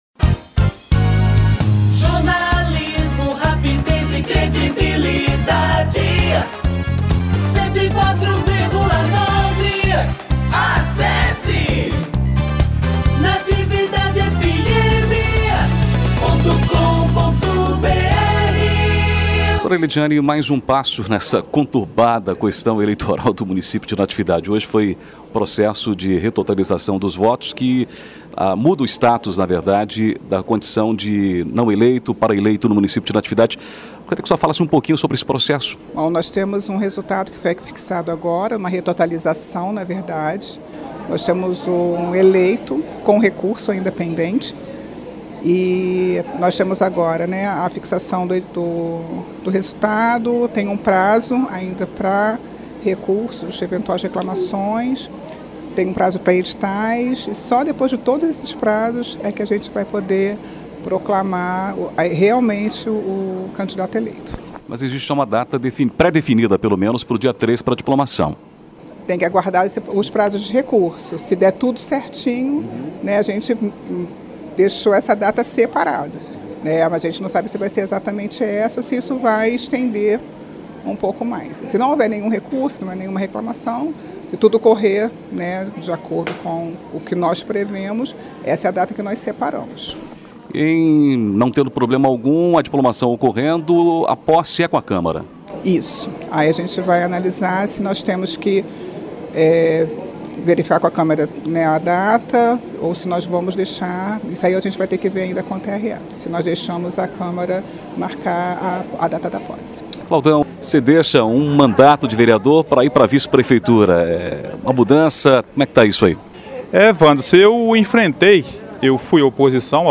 21 de maio de 2025 ENTREVISTAS, NATIVIDADE AGORA
A Rádio Natividade acompanhou no início da tarde desta terça-feira (20), o ato de reprocessamento dos votos da eleição de 2024 para prefeito de Natividade.
O jornalismo da emissora, conversou com o Taninho, seu vice, Claudão, além da juíza Leidejne Chieza.